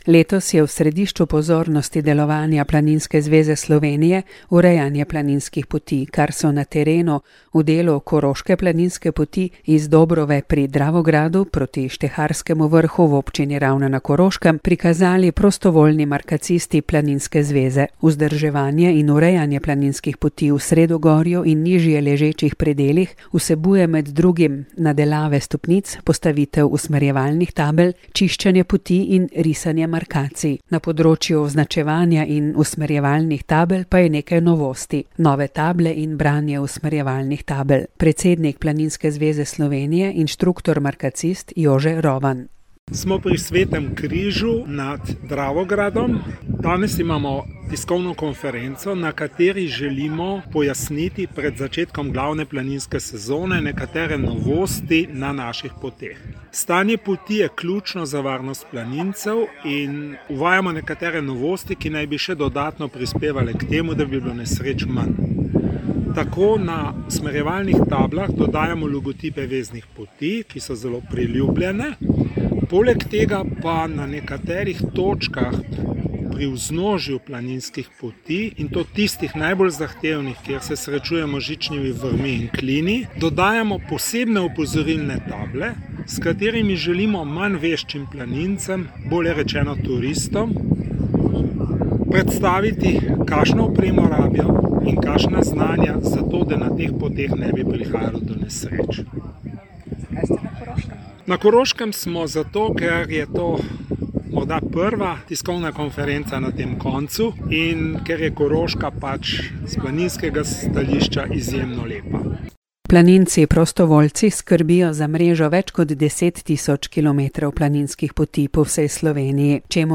Novinarska konferenca Planinske zveze Slovenije